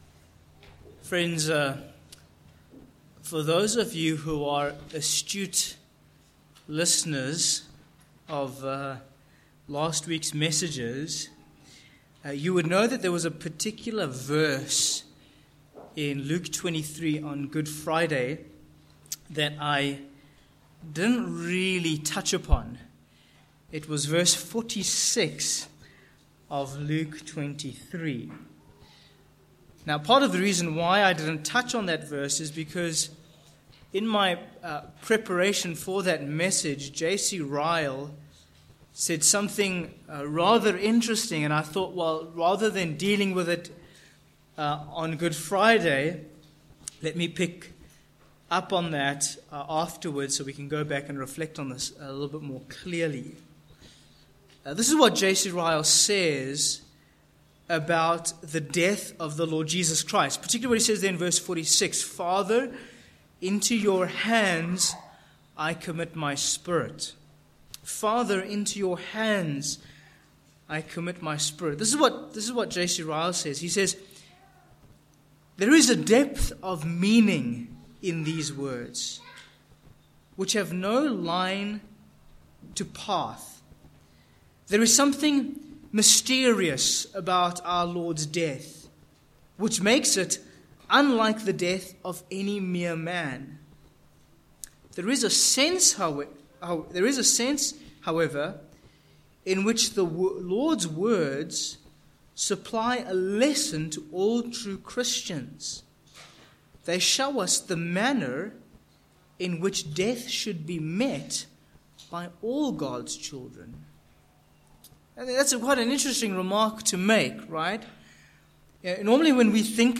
Sermon points 1. The Importance of Cultivating Intimacy with God